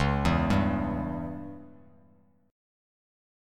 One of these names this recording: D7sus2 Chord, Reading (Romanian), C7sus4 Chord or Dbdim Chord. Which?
Dbdim Chord